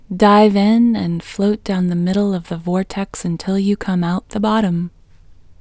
IN – the Second Way – English Female 24